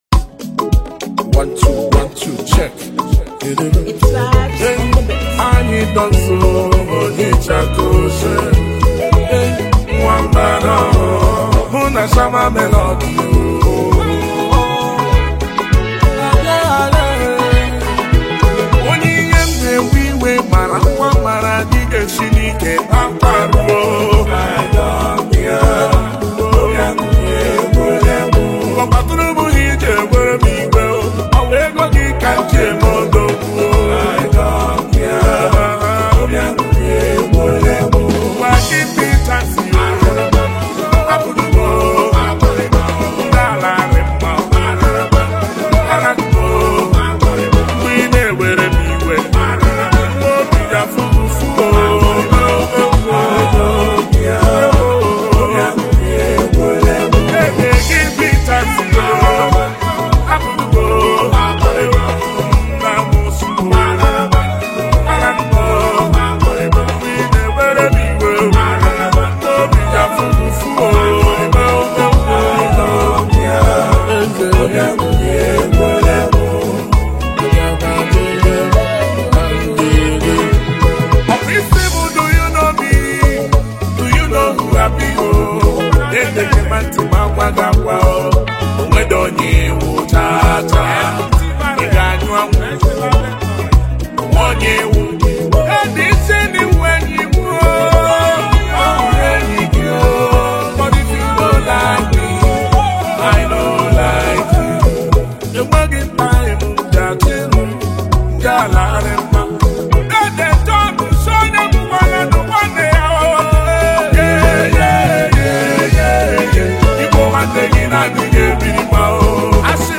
Genre: Nigerian Gospel Songs